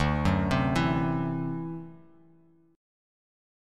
D9 Chord
Listen to D9 strummed